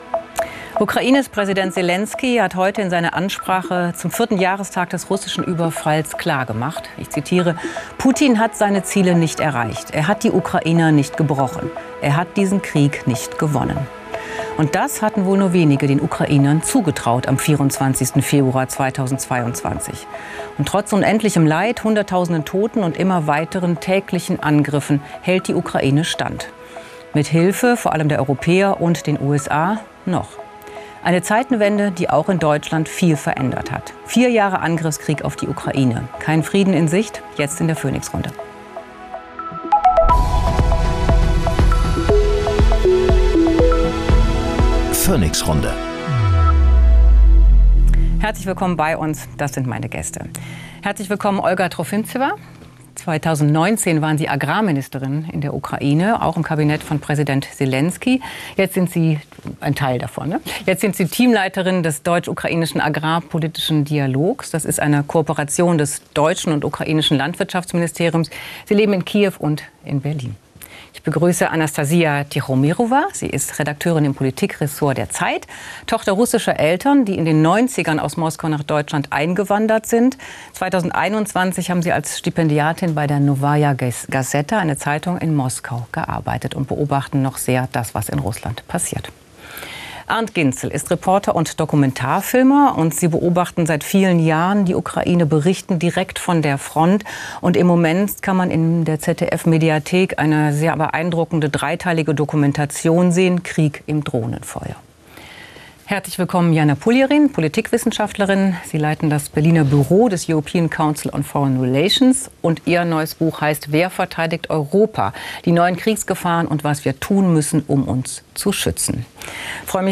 Olga Trofimtseva, ehem. stellv. Agrarministerin in der Ukraine
Journalist und Kriegsreporter